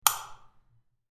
light-switch-sound-effect.mp3